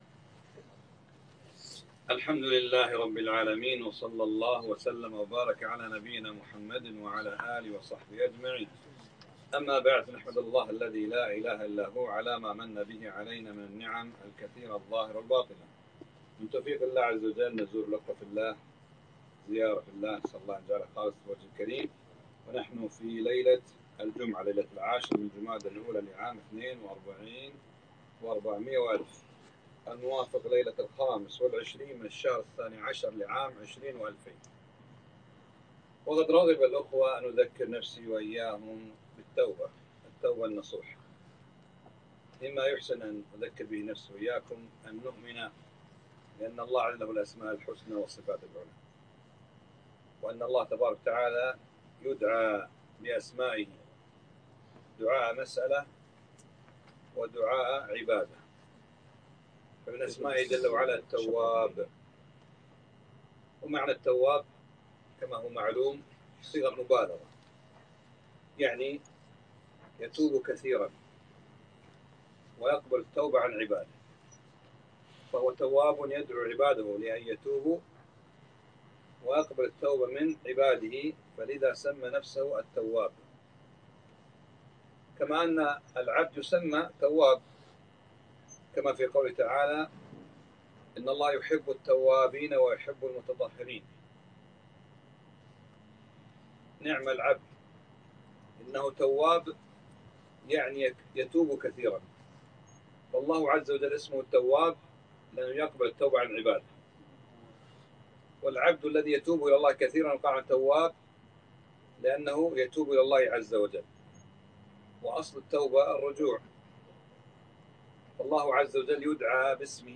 محاضرة نافعة - التوبة 9-5-1442